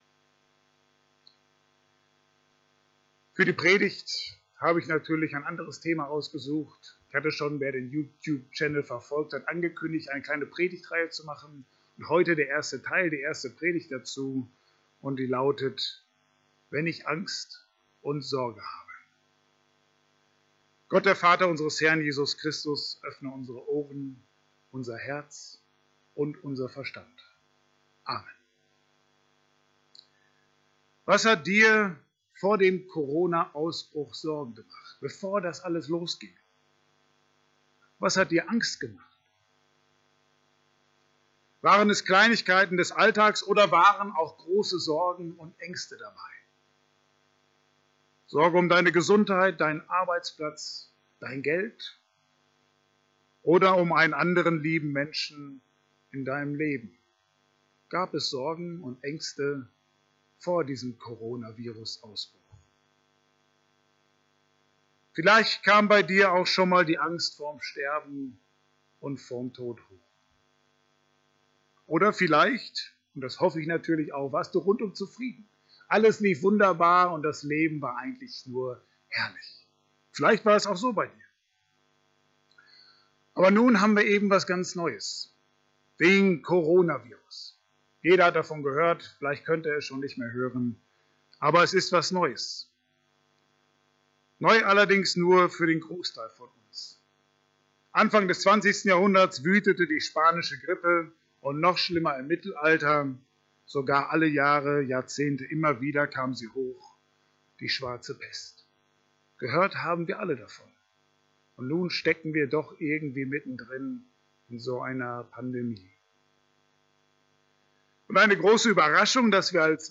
Petrus 5,7, Johannes 16,33 Dienstart: Gottesdienst « Predigt über 2.